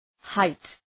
Προφορά
{haıt}